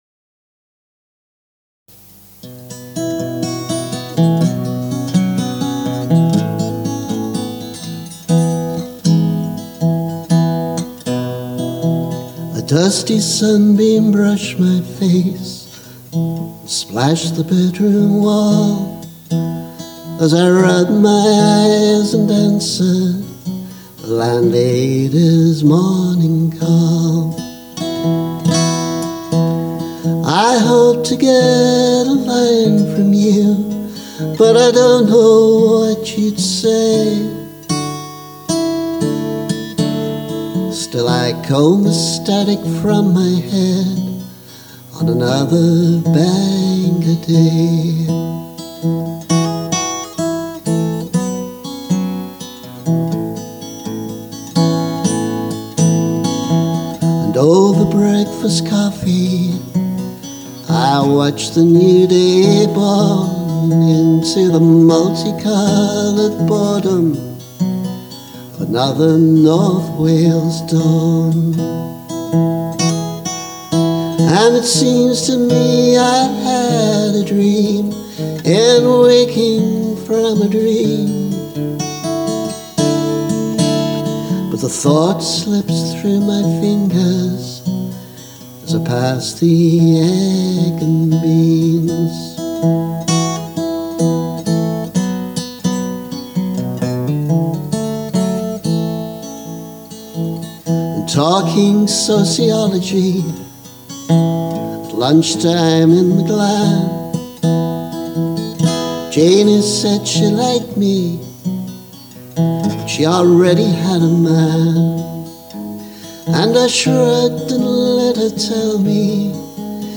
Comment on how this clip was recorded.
Remastered: